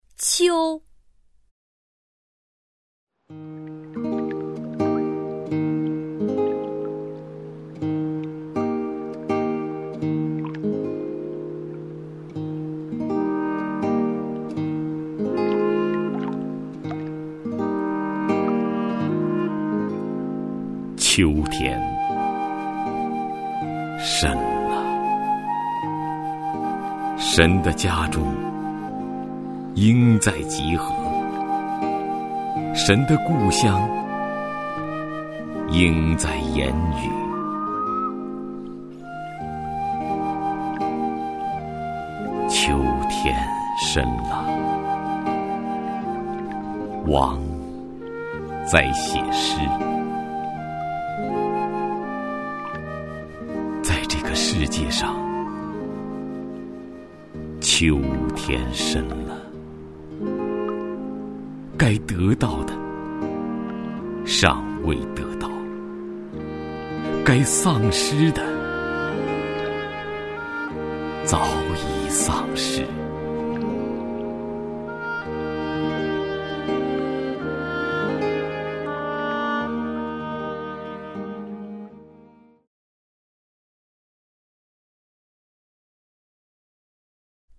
徐涛朗诵：《秋》(海子) 海子 名家朗诵欣赏徐涛 语文PLUS